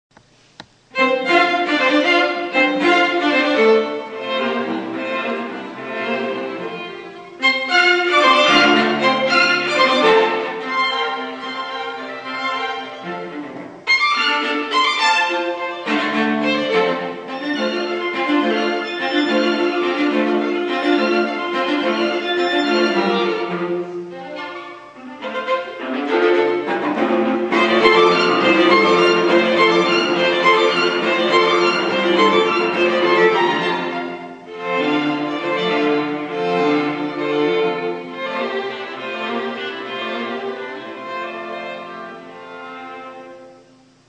스케르초 악장이며, F장조, 3/4박자, 3부 형식이다. 제2바이올린과 첼로가 옥타브의 강주로 곧바로 주제를 연주한다.